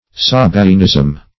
Search Result for " sabaeanism" : The Collaborative International Dictionary of English v.0.48: Sabaeanism \Sa*bae"an*ism\, n. Same as Sabianism .
sabaeanism.mp3